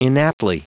Prononciation du mot inaptly en anglais (fichier audio)
inaptly.wav